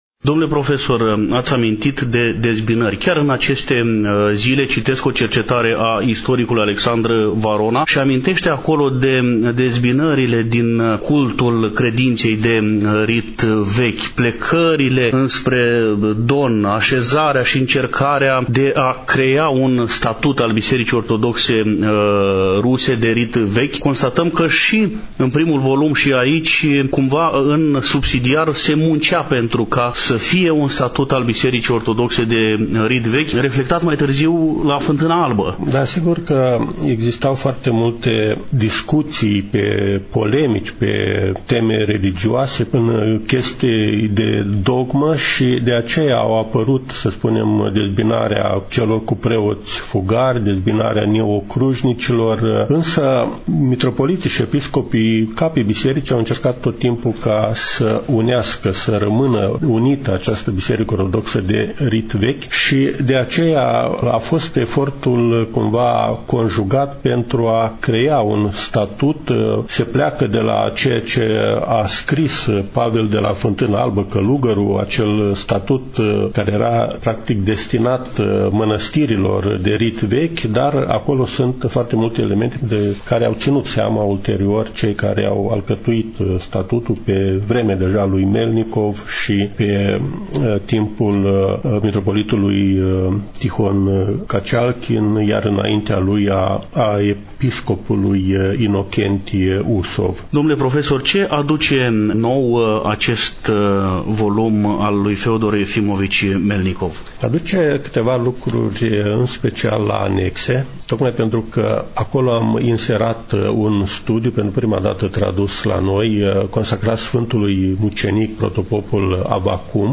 stăm de vorbă cu domnul profesor universitar